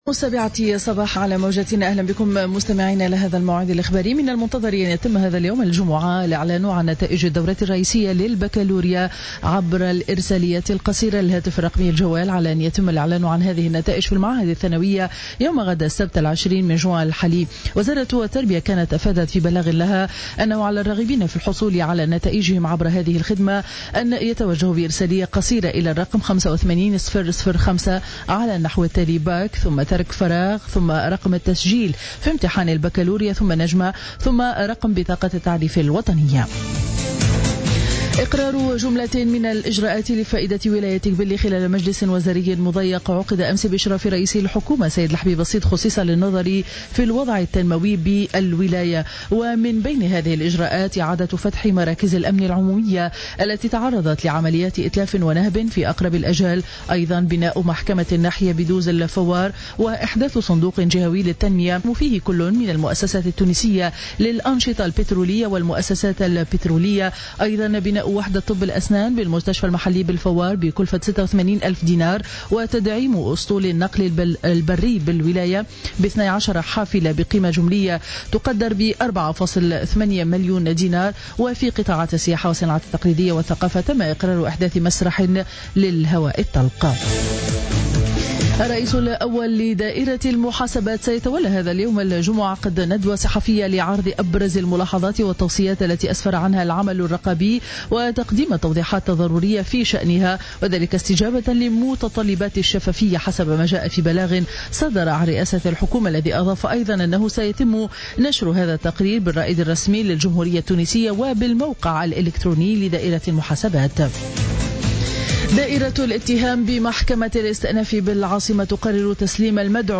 نشرة اخبار السابعة صباحا ليوم الجمعة 19 جوان 2015